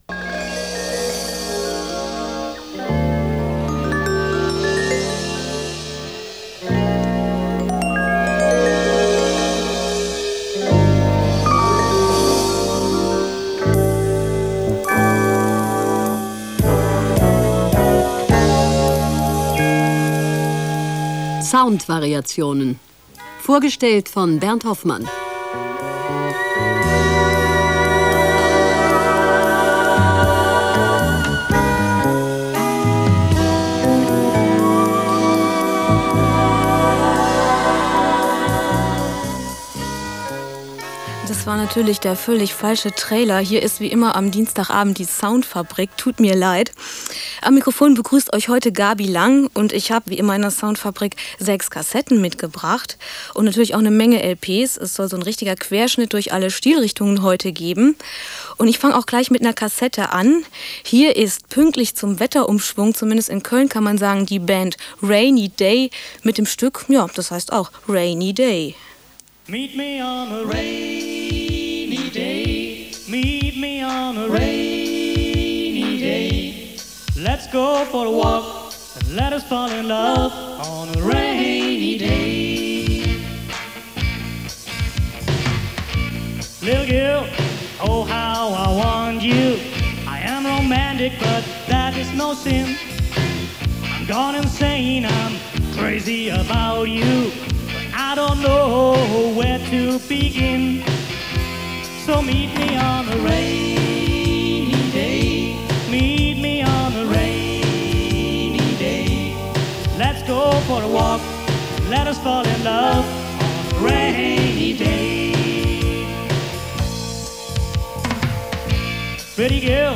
Radiosendung Originalbesetzung